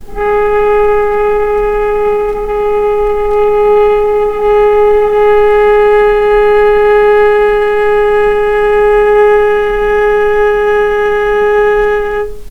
vc-G#4-pp.AIF